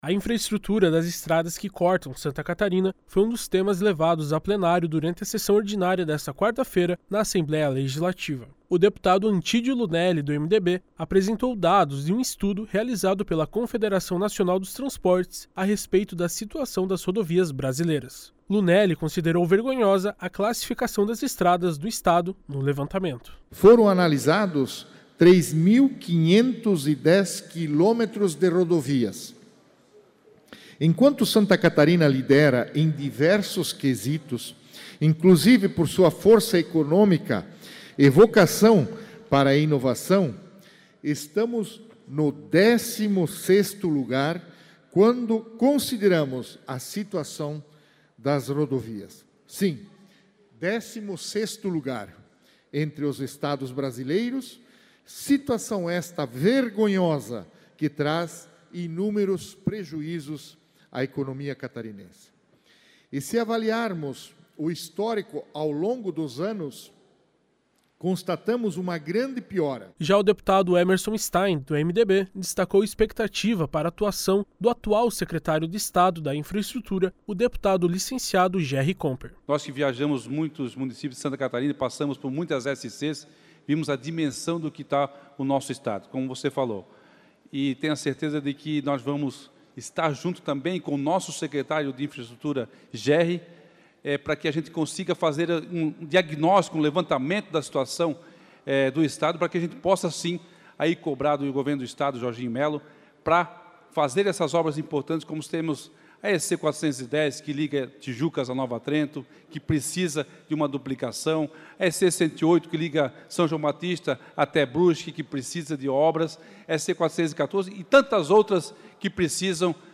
Entrevista com:
- deputado Antídio Lunelli (MDB);
- deputado Emerson Stein (MDB);
- deputado Napoleão Bernardes (PSD).